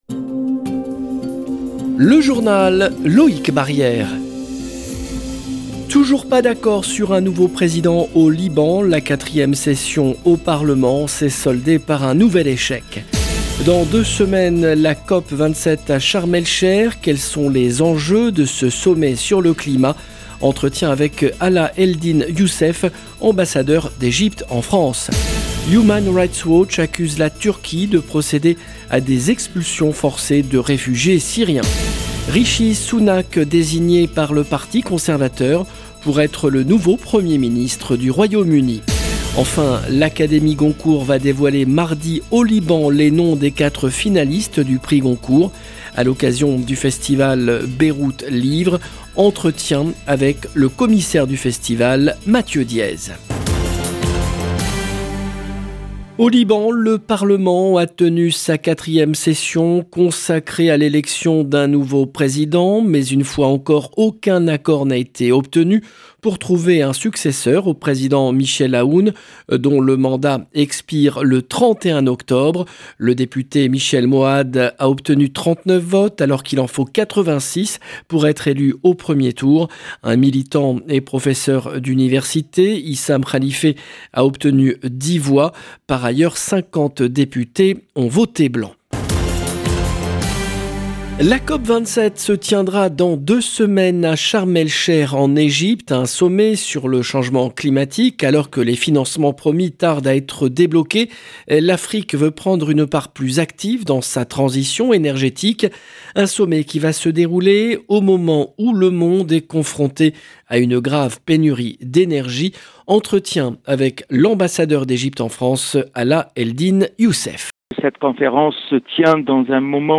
Entretien avec Alaa Eldin Youssef, ambassadeur d’Egypte en France. Human Rights Watch accuse la Turquie de procéder à des expulsions forcées de réfugiés syriens. Rishi Sunak désigné par le Parti conservateur pour être le nouveau premier ministre du Royaume-Uni.